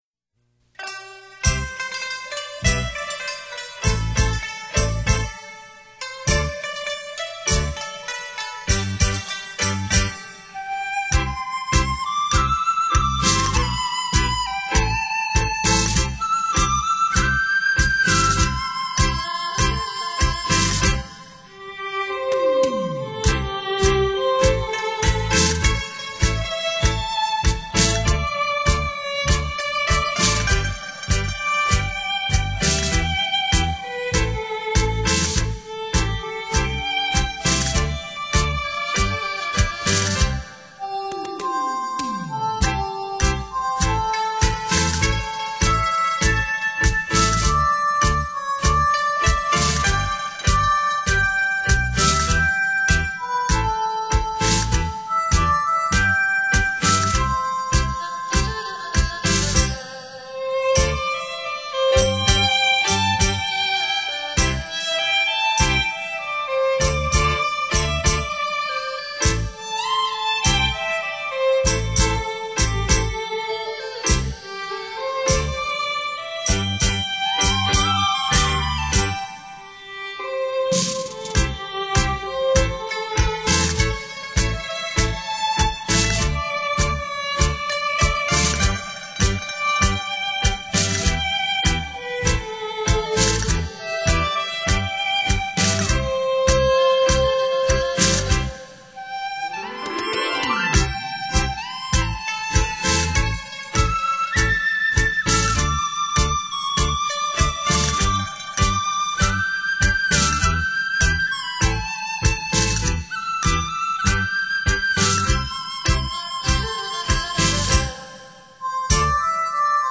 扬琴,琵琶,笛,箫,笙等配合西乐合奏出雅俗共赏的音乐~